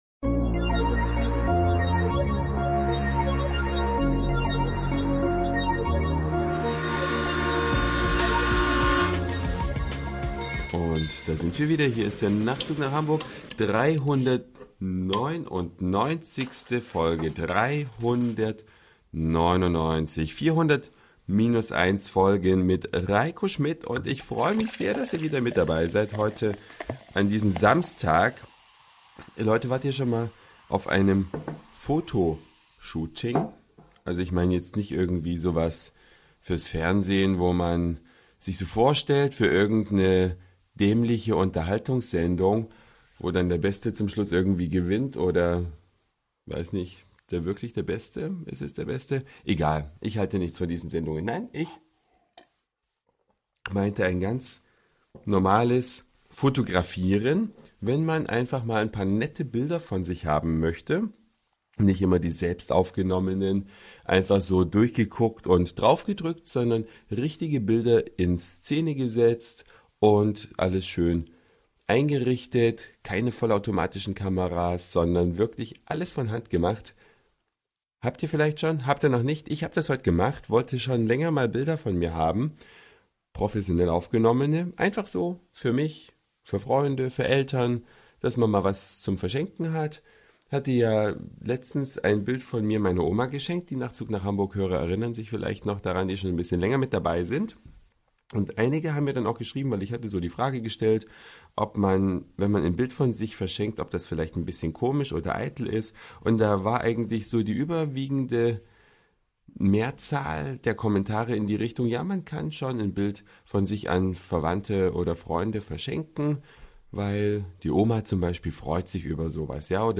Eine Reise durch die Vielfalt aus Satire, Informationen, Soundseeing und Audioblog.
Ein Nachmittag im Freihafen. Einige Ecken ohne Touristen.
Eine Alsterwiese im Spätsommersonnenschein.